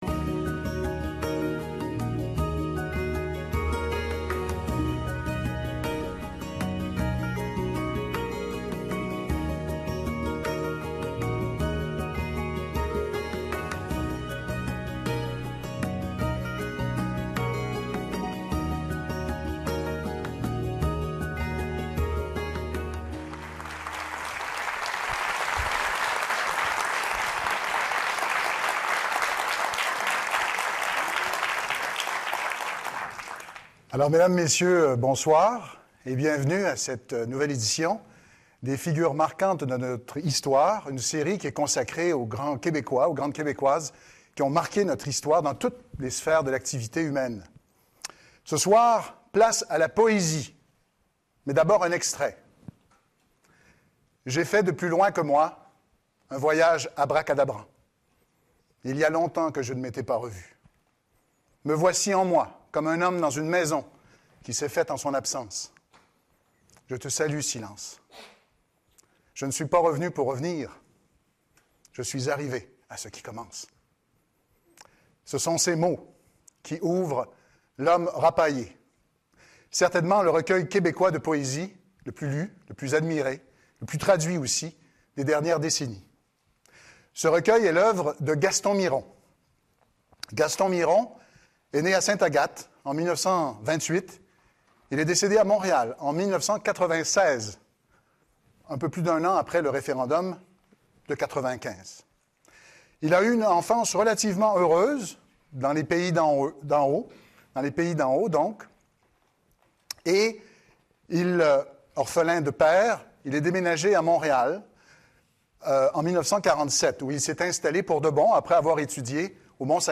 Par son œuvre de poète, mais aussi par son activité d’éditeur et à travers sa pensée linguistique et politique, Gaston Miron incarne d’une manière exemplaire l’émergence, durant les années 1960 et 1970, d’une littérature québécoise moderne qui s’insérait en même temps dans un grand récit national. Cette conférence voudrait examiner les sources historiques de ce projet, qui remontent au milieu du 19e siècle, mettre en lumière les étapes principales de cette évolution, tout en mettant en lumière la contribution singulière du poète de L’homme rapaillé.